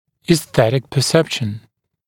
[iːs’θetɪk pə’sepʃn][и:с’сэтик пэ’сэпшн]эстетическое восприятие